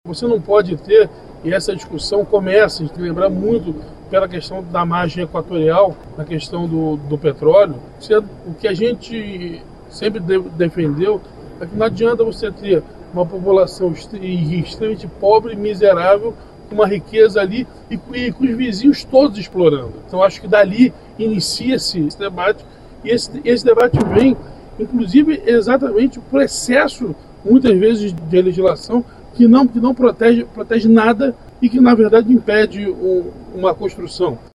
Durante o encontro em Curitiba, o governador do Rio de Janeiro, Cláudio Castro, defendeu que o país encontre um ponto de equilíbrio entre proteção ambiental e crescimento econômico.